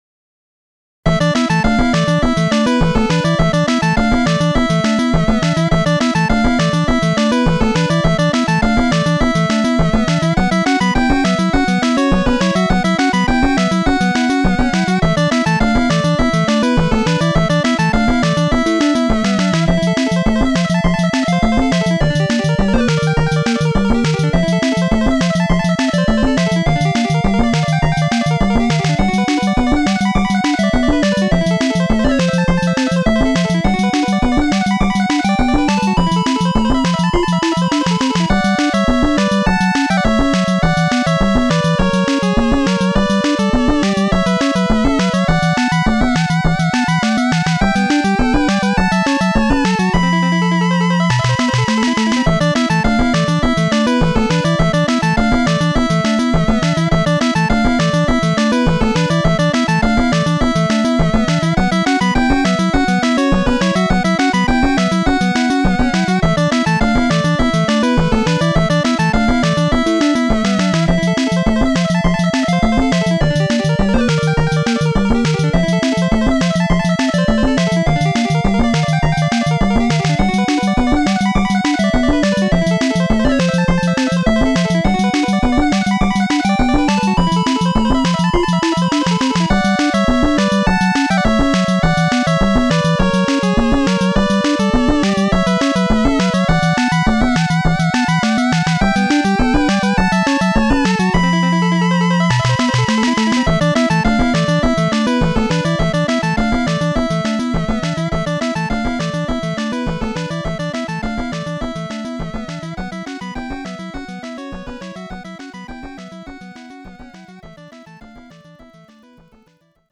サウンドを、YAMAHAのXG音源「MU100」を用いて擬似的に再現して制作したオリジナル曲たちです。
MP3ファイルは全て「YAMAHA MU100」で制作したMIDIデータを「YAMAHA MU1000EX」で再生、
ラスボスBGM。ある種の緊迫感を醸しつつも、どこか和風の雰囲気ｗ